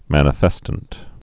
(mănə-fĕstənt)